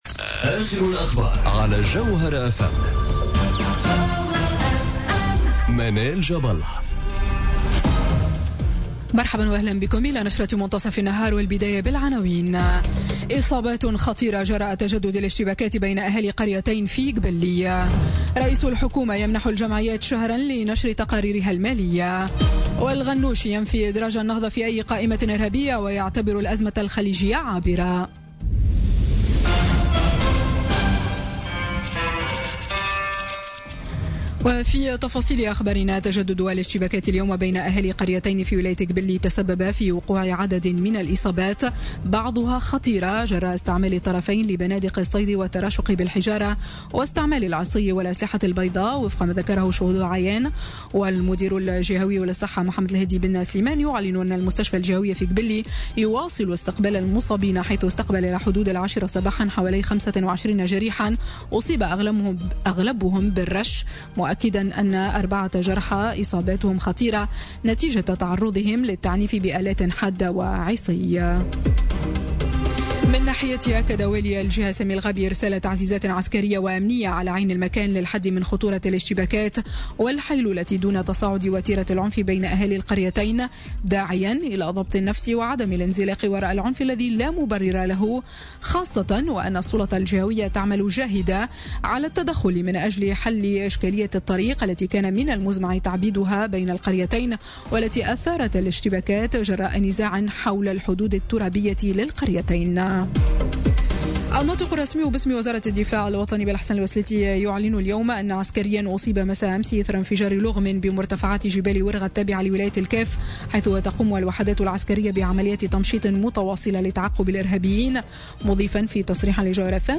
نشرة أخبار منتصف النهار ليوم السبت 10 جوان 2017